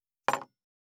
229,机に物を置く,テーブル等に物を置く,食器,グラス,コップ,工具,小物,雑貨,コトン,トン,ゴト,ポン,
コップ効果音物を置く